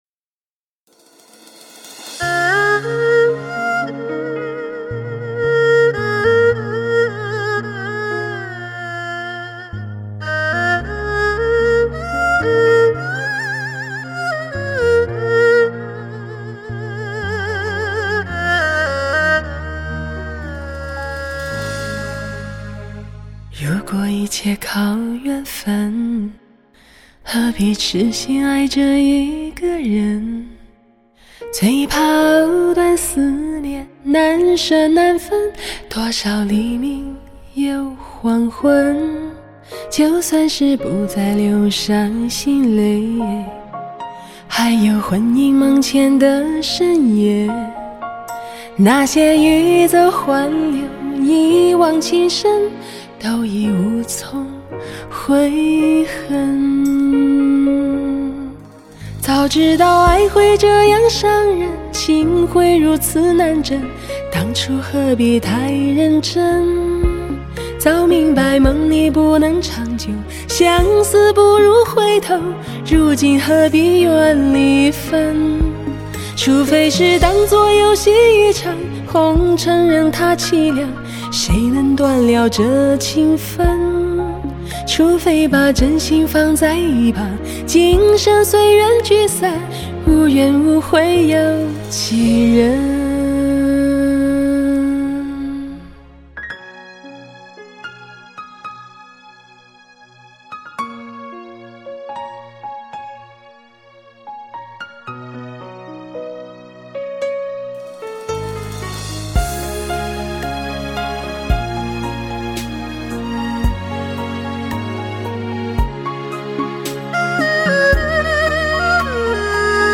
乐声录音棚
动听绝伦的人声响宴，无法抗拒的奢华诱惑。